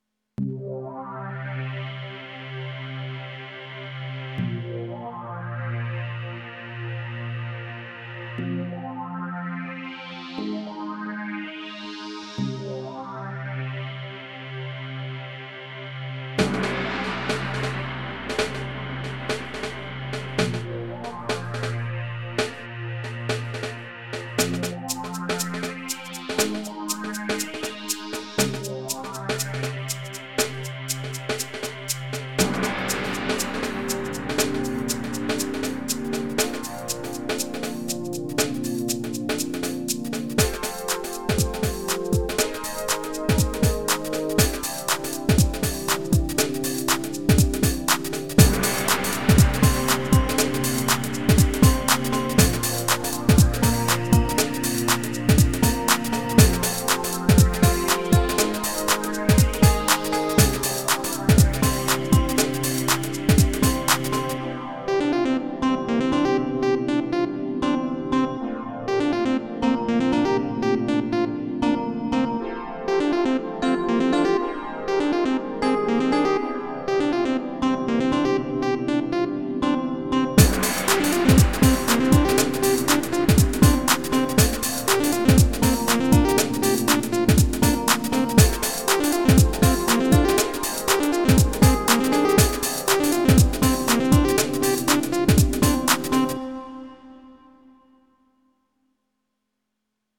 Acid